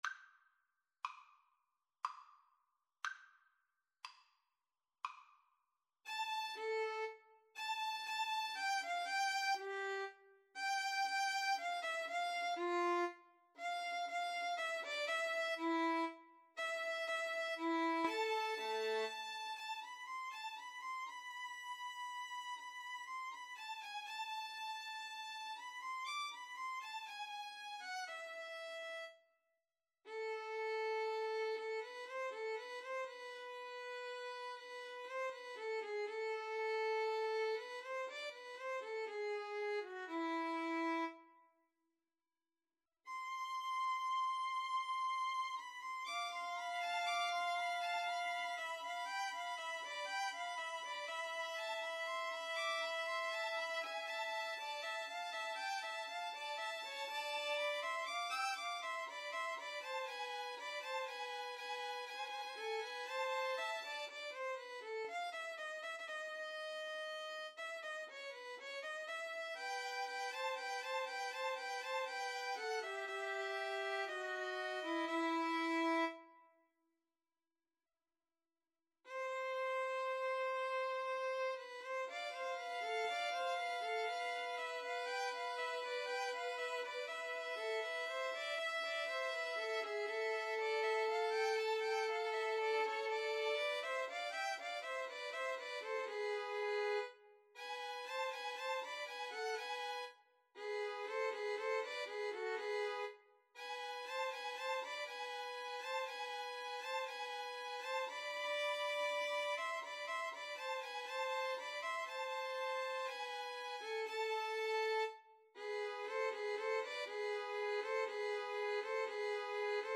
3/4 (View more 3/4 Music)
Classical (View more Classical Violin Trio Music)